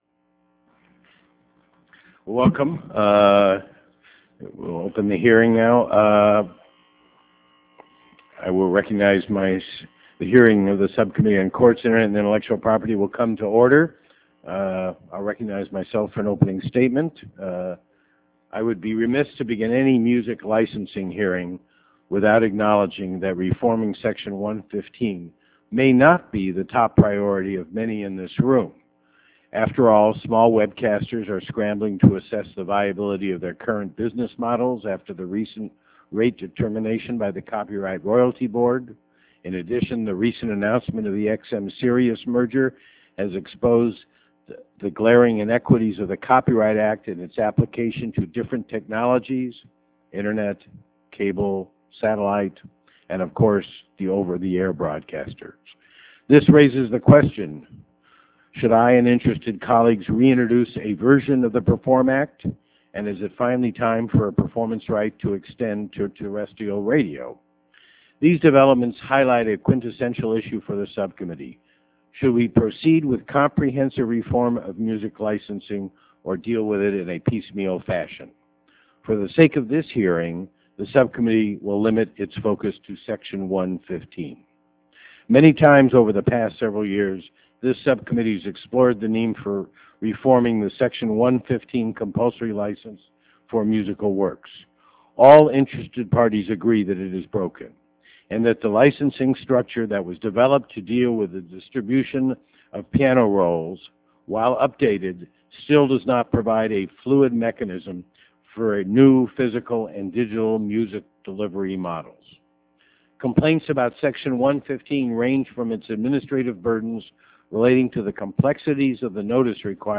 Notes: Includes opening statements and question-and-answer session .